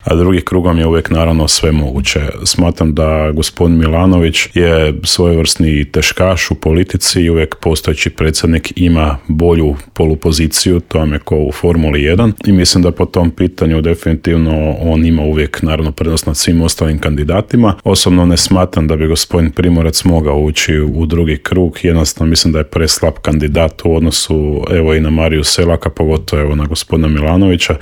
Kakva je ponuda i što sve čeka posjetitelje u Intervjuu Media servisa otkrio nam je tamošnji gradonačelnik Dario Zurovec.